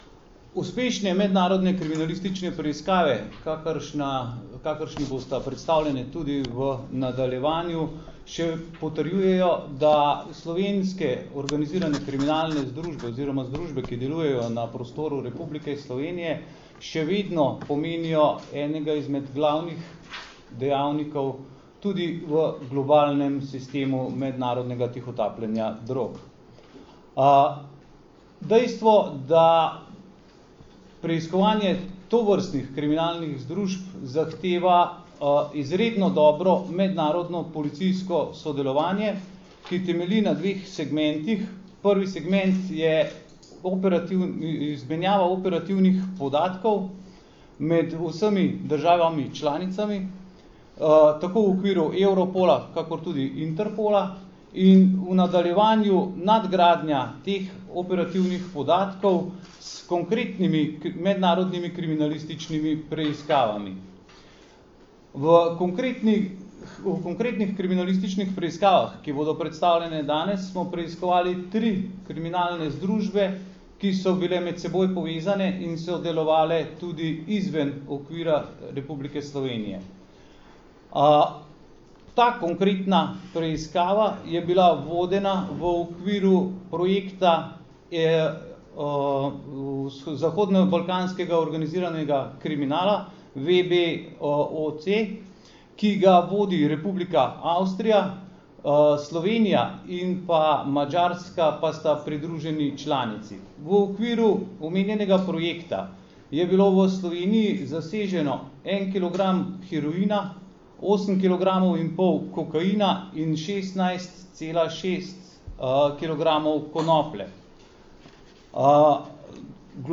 Na današnji novinarski konferenci smo podrobneje predstavili uspešen zaključek dlje časa trajajoče in obsežne preiskave mednarodne razsežnosti oz. več sočasnih in medsebojno prepletenih kriminalističnih preiskav zoper tri med sabo povezane hudodelske združbe, ki so v Sloveniji in v tujini izvrševale kazniva dejanja s področja prepovedanih drog.
Zvočni posnetek izjave mag. Boštjana Lindava, (mp3)
pomočnika direktorja Uprave kriminalistične policije GPU